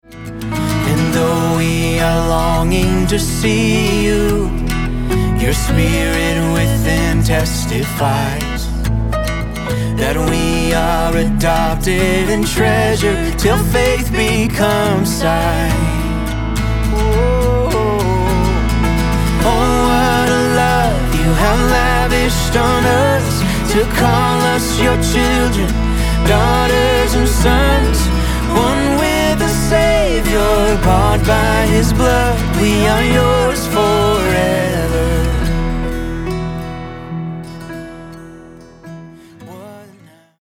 Guitar Chart - Recorded Key (E)